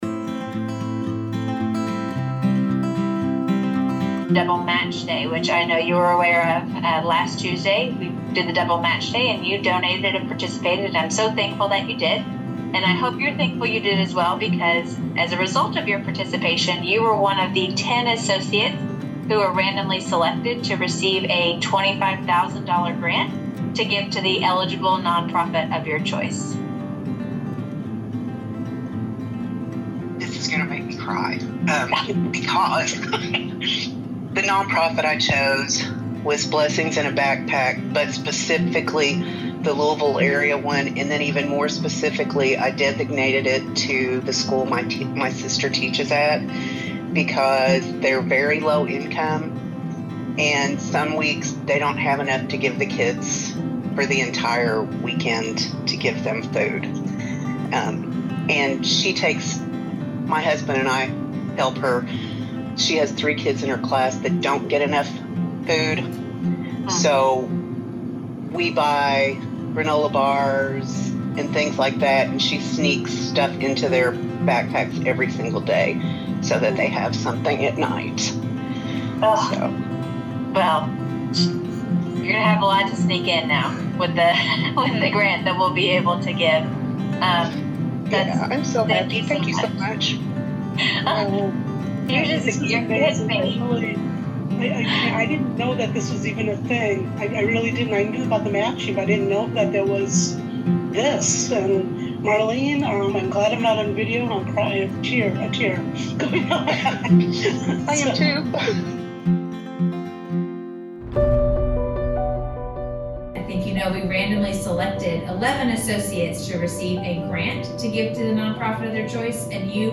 The grant winners had truly heartwarming reactions when they learned of their prize, and we are honored to share a brief audio compilation of a few of the reactions.